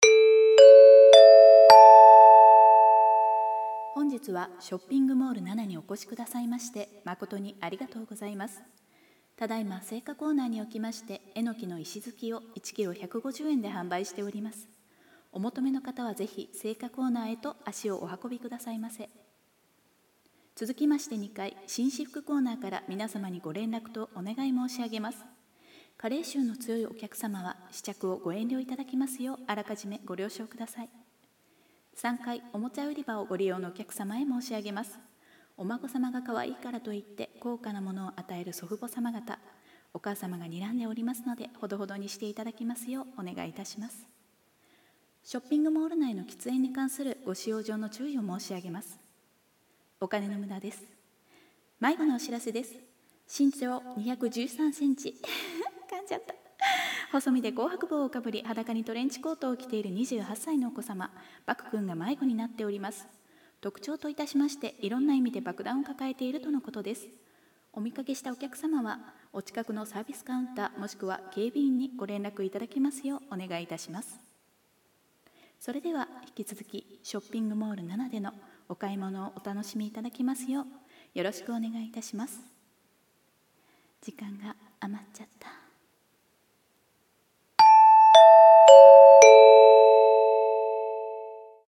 声劇〖店内アナウンス〗 / 【声劇】【一人用】【アナウンス】【ギャグ】